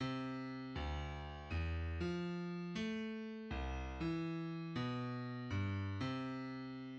{\clef bass \tempo 4=120 \key ees \major r2 r4 bes, c4. c8 ~ c4 c d4. d8 ~d4 d ees4. ees8 ~ ees4 aes g2}\midi{}